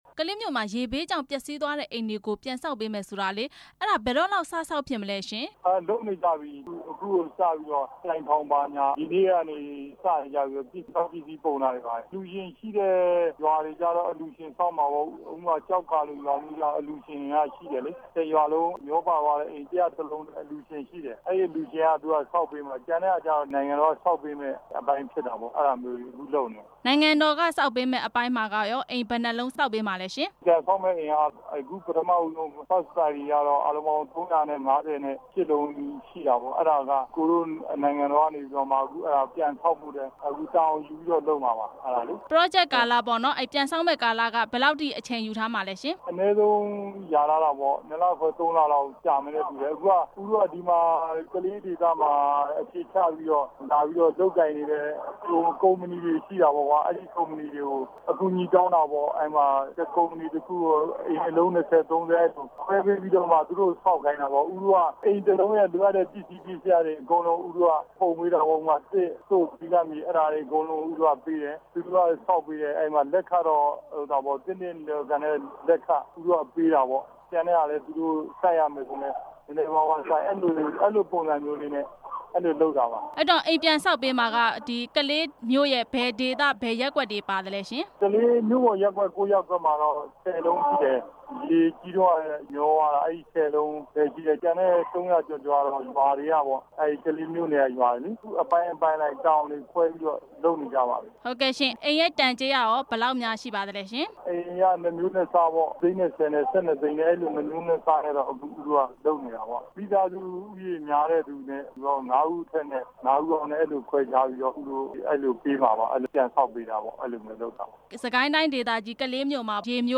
ကလေး ရေဘေးဒုက္ခသည်တွေ အတွက် အိမ်ဆောက်ပေးနေတဲ့ အကြောင်း မေးမြန်းချက်